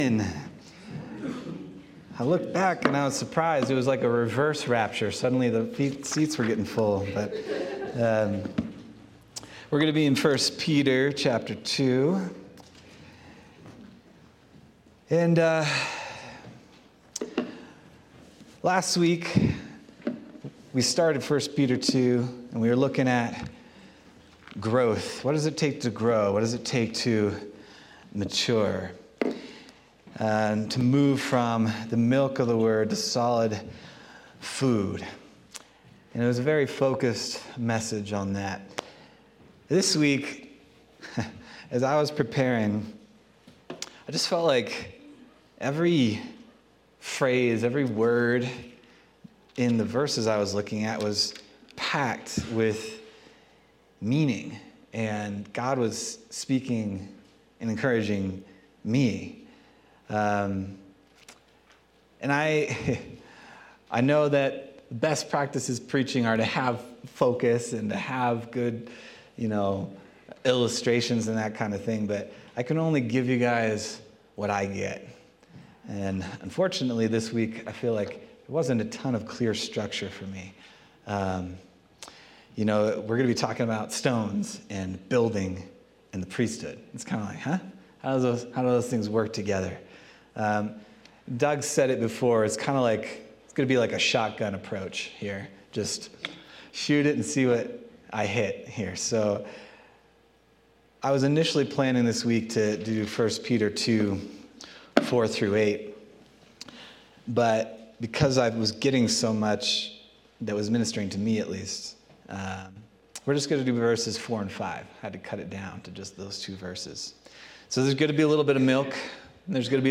January 26th, 2025 Sermon